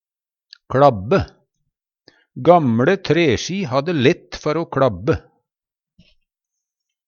kLabbe - Numedalsmål (en-US)